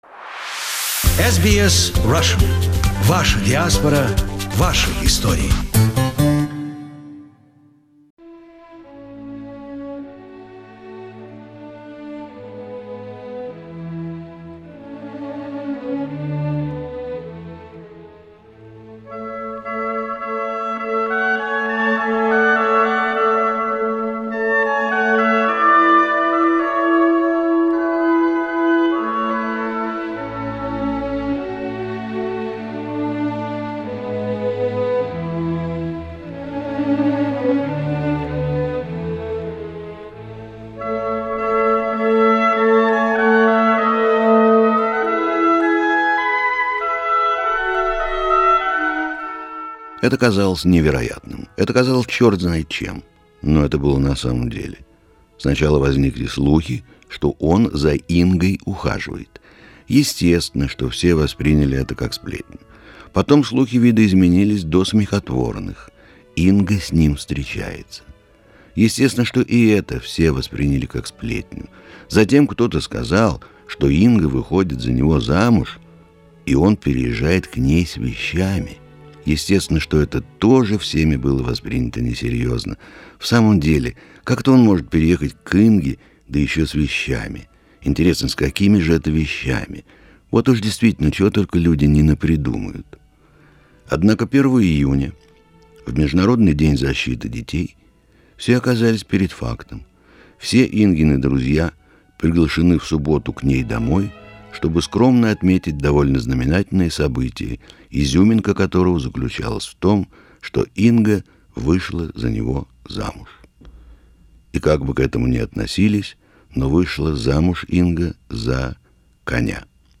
During one of his visits, in 2002, we taped Arkady Arkanov reading three of his stories which we produced and later aired several times. And as often with Arkanov, they are far from just funny stories, in fact, they quite sad and philosophical miniatures.